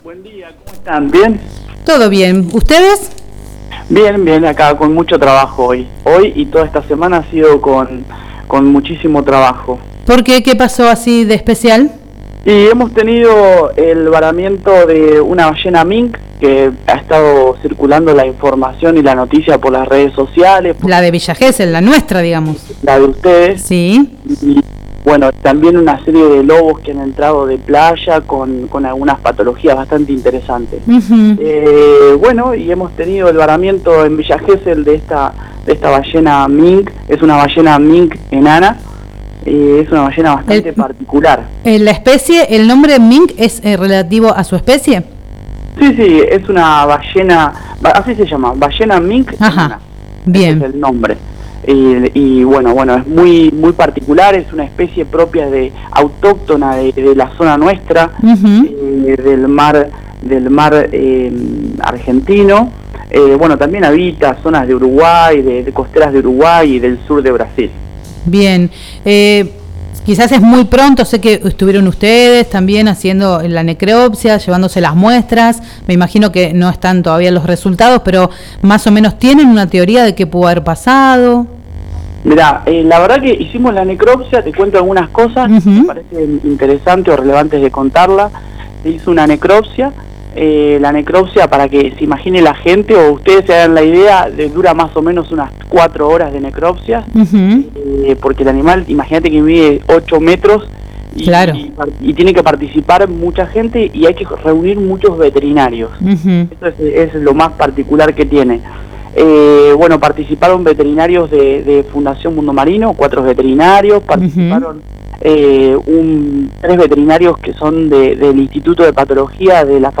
en una entrevista en Por la 3 Derecho radio.